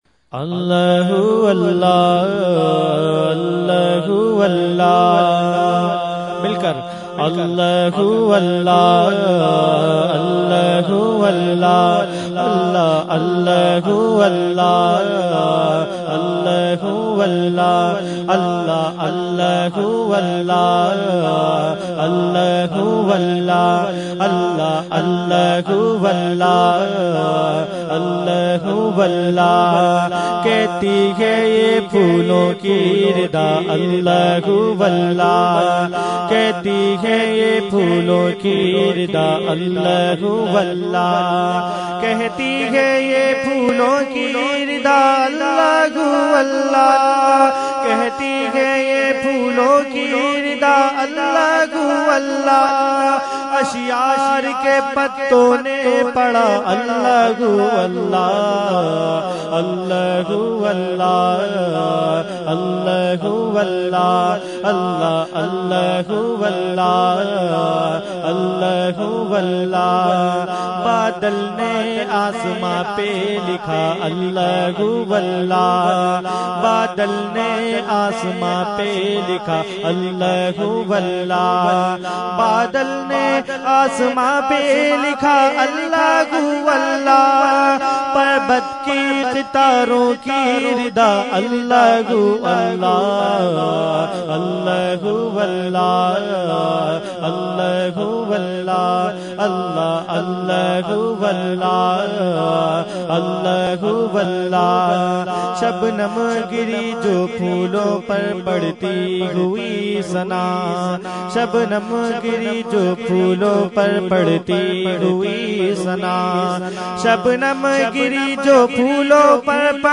Category : Hamd | Language : UrduEvent : 11veen Sharif Lali Qila Lawn 2015